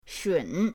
shun3.mp3